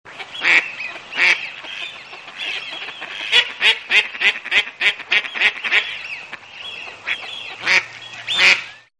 Mallard Duck